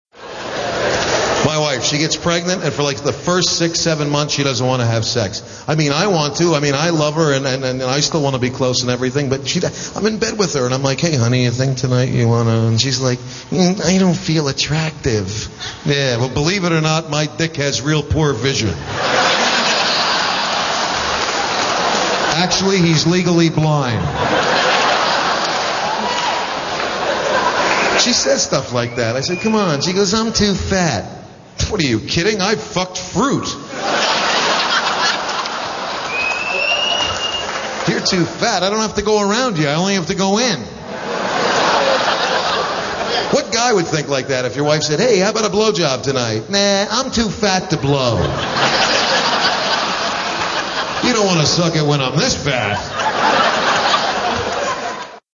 Category: Comedians   Right: Personal
Tags: Comedian Robert Schimmel clips Robert Schimmel audio Stand-up comedian Robert Schimmel